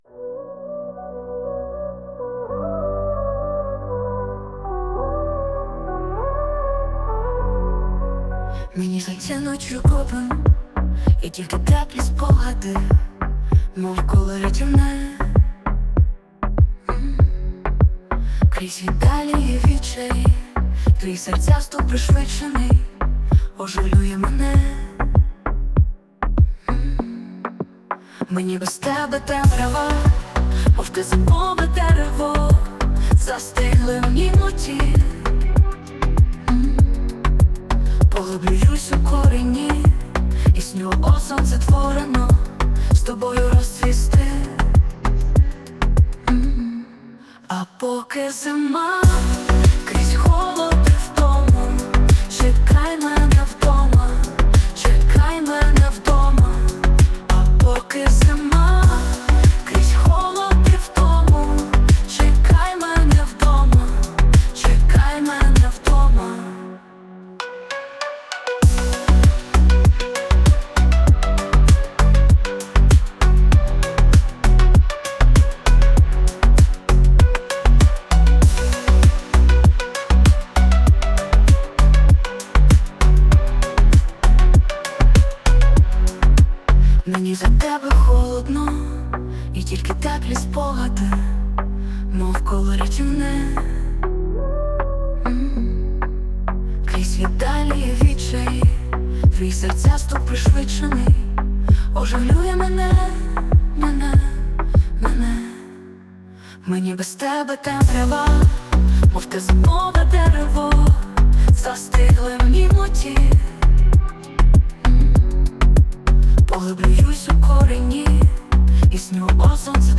ТИП: Пісня
СТИЛЬОВІ ЖАНРИ: Ліричний
16 чудове поєднання Вашого вірша і голосу Ші! Сподобалось! hi
16 16 Дуже-дуже гарно tender Чуттєво і мелодійно flo11